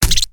EnemyHurt.ogg